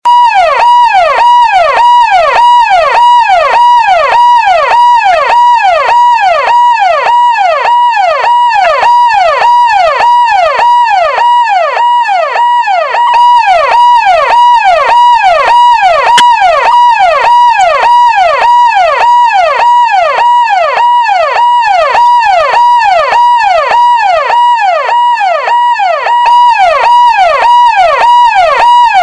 Android, Sirens, Ambulans siren